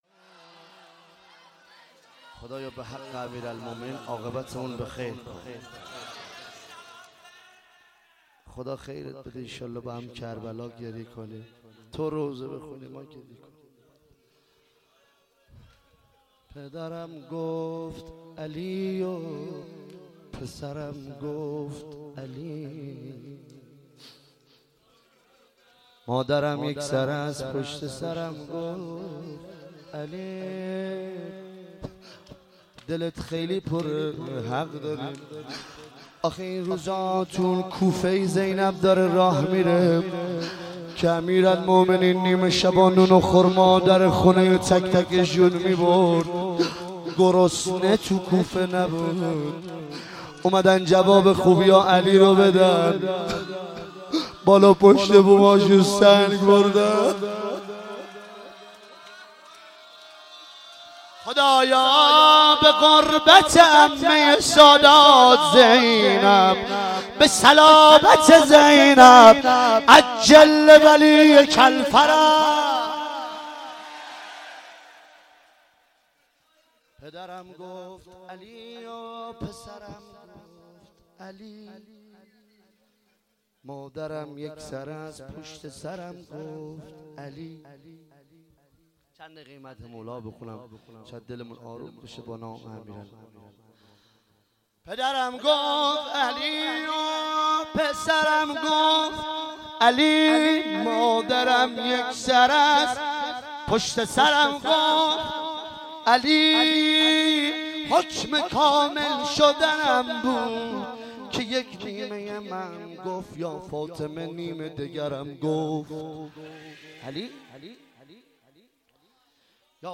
شب 17 محرم 95_روضه پایانی
روضه مداحی